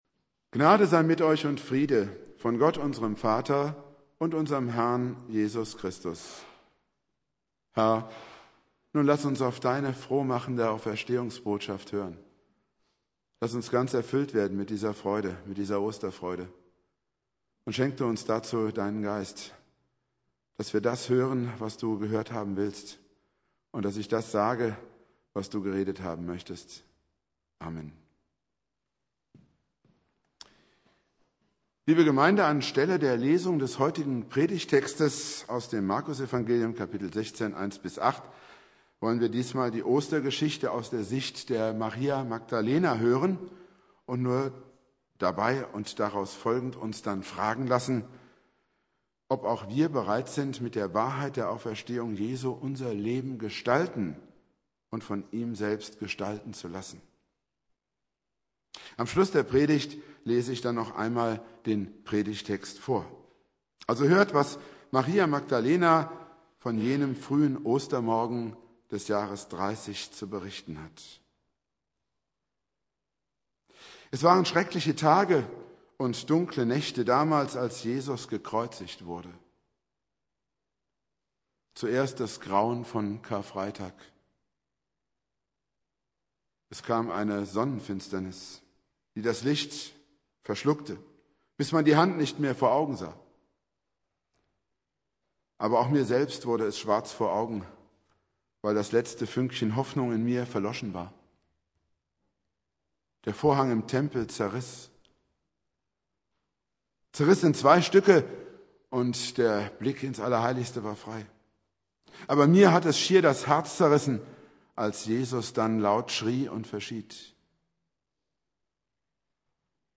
Predigt
Ostermontag